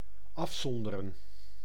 Ääntäminen
IPA: /ap.stʁɛʁ/